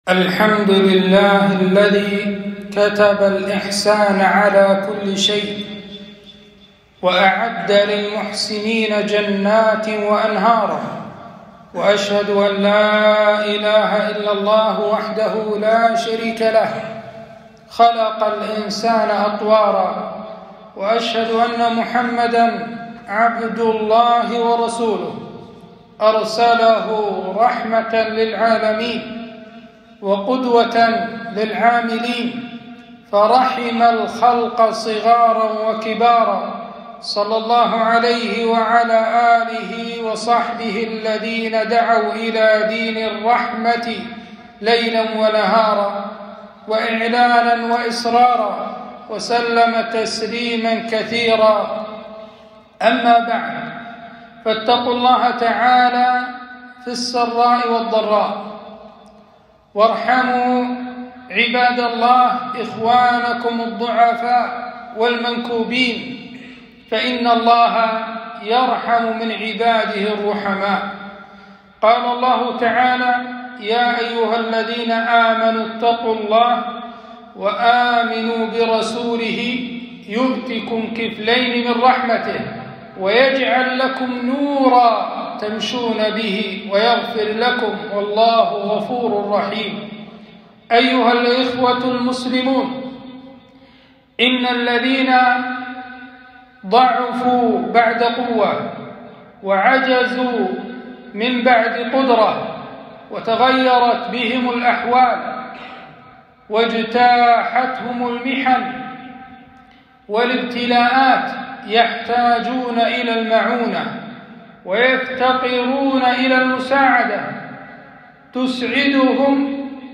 خطبة - ارحموا من في الأرض يرحمكم من في السماء